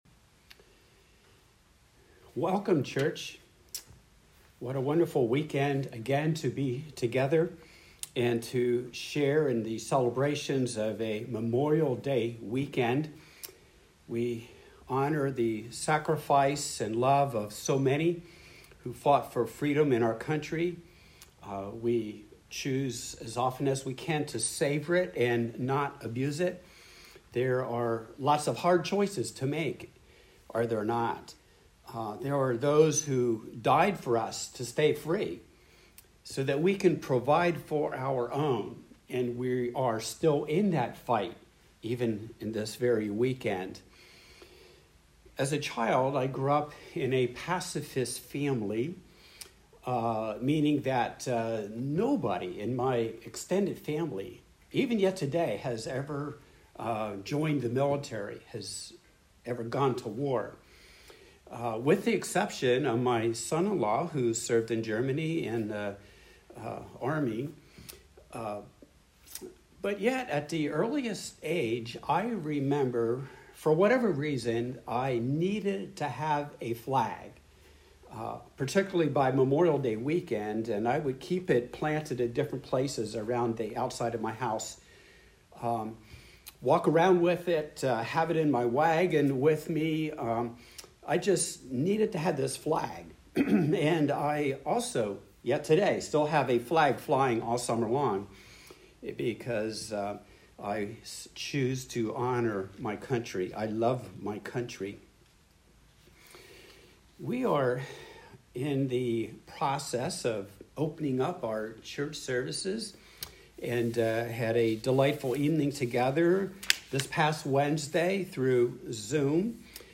Passage: Mark 2:13-3:6 Service Type: Sunday Worship